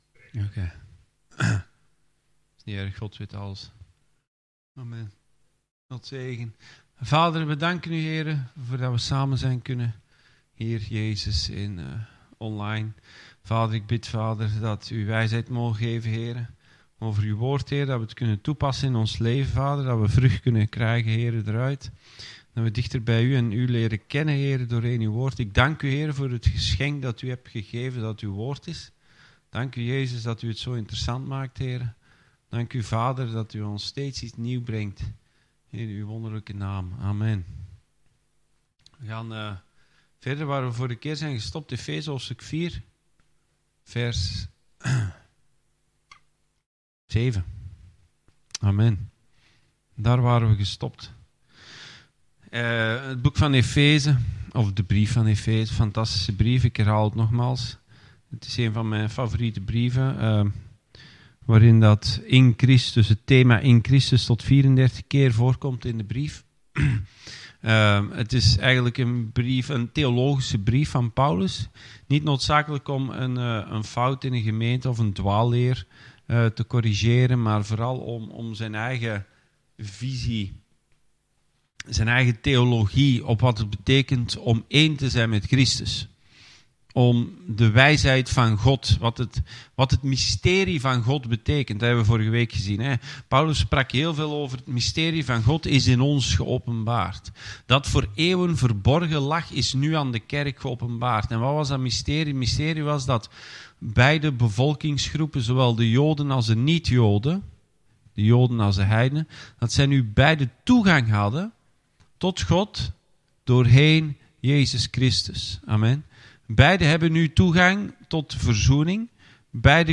Bijbelstudie: Efeziërs 4